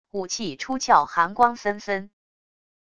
武器出鞘寒光森森wav音频